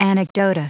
Prononciation du mot anecdota en anglais (fichier audio)
Prononciation du mot : anecdota